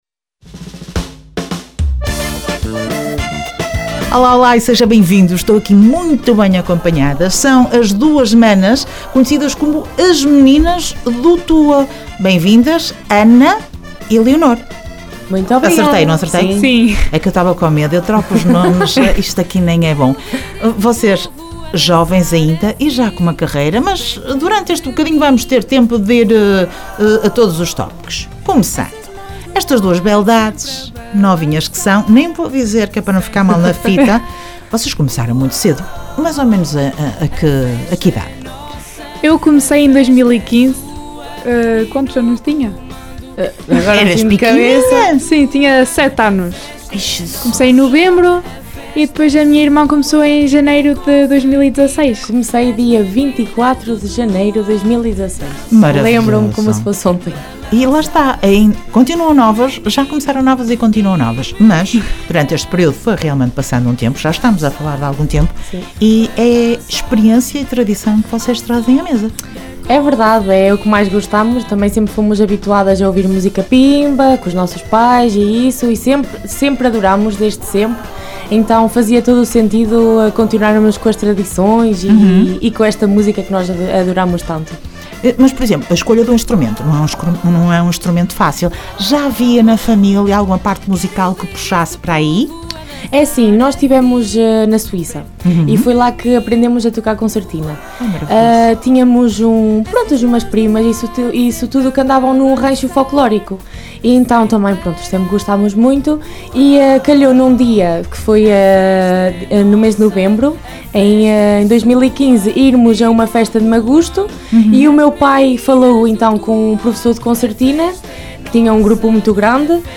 Entrevista Meninas do Tua dia 27 de Agosto de 2025